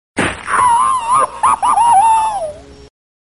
Tom Screammmmmmmm Bouton sonore